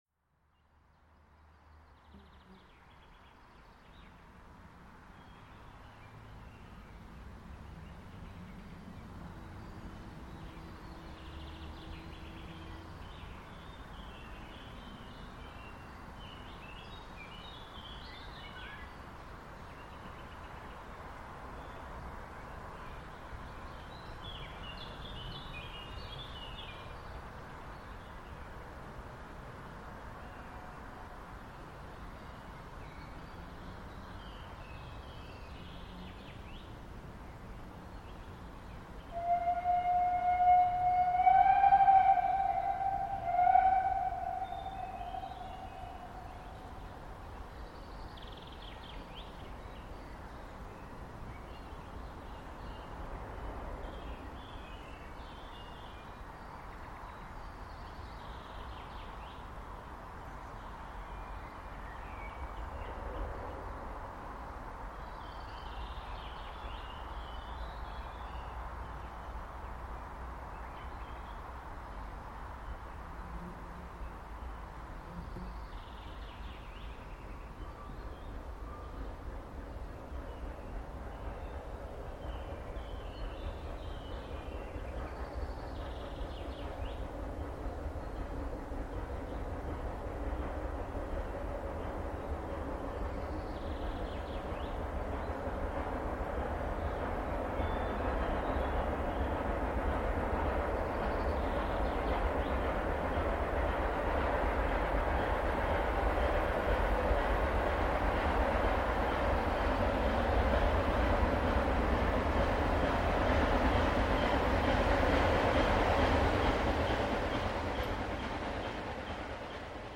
Leider wird dieses Geräusch parallel zu besserer und lauterer Fahrweise ebenfalls viel lauter.
Lok 11  mit 7-Wagen-Infozug zurück Kupferkammerhütte→Benndorf, aufgenommen ziemlich weit hinten in der Kurfe im kleinen Wald hinter der Ausfahrt aus Hettstedt-Kupferkammerhütte, um 13:24h am 29.05.2025. Ganz schön schnelle fahrweise hier hinten, wenn man sooo schön laut fährt...:-).